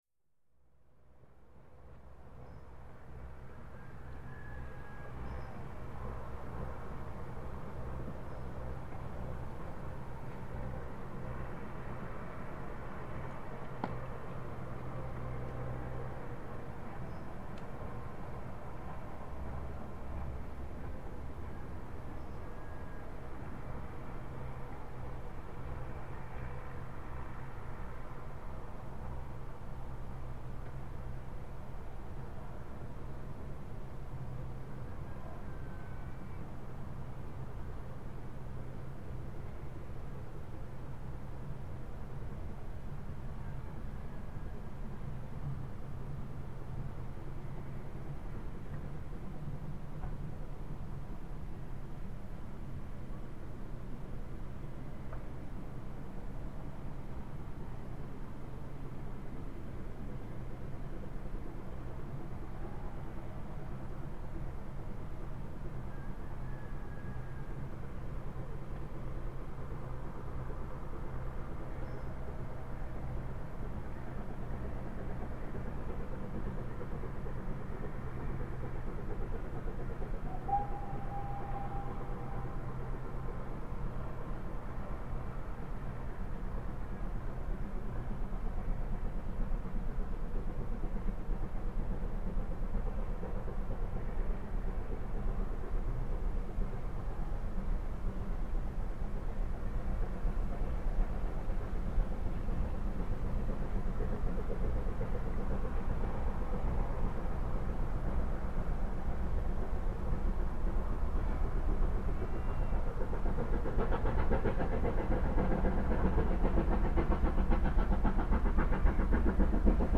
4277 kommt mit ihrem Zug bei Green End recht flott den Berg hochfahren, um 15:55h am 08.08.2000.